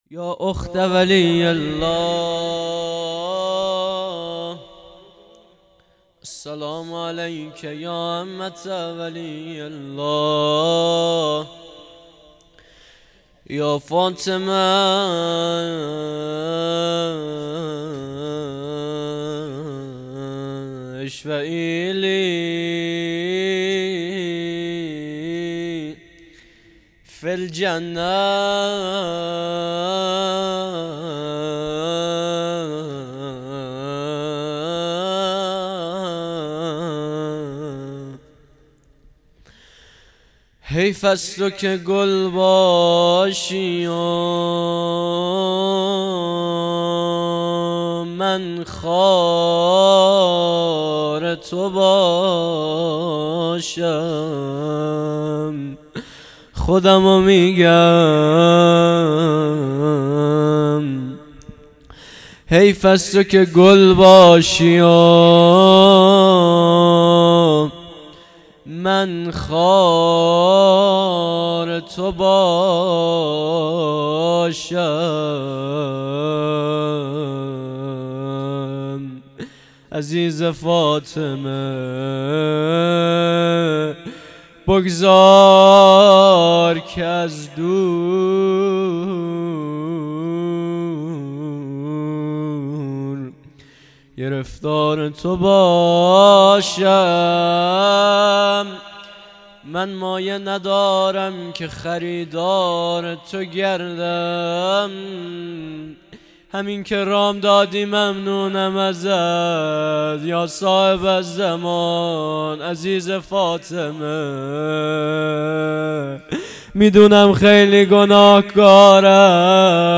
هیئت مکتب الرضا علیه السلام دلیجان
درد دل | یا فاطمه اشفعی لی فی الجنة
مسجد امام موسی بن جعفر علیه السلام | وفات حضرت معصومه سلام الله علیها